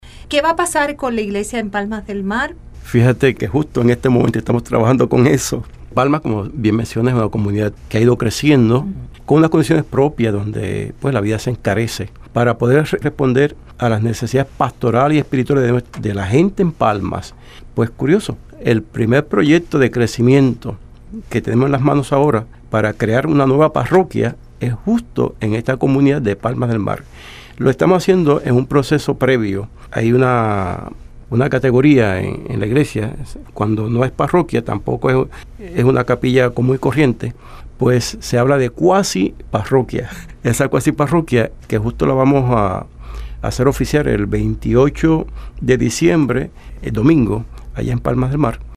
Así lo adelantó este viernes, Eusebio Ramos Morales, Obispo de la Diócesis de El Yunque, y quien tiene a su cargo la región de Fajardo.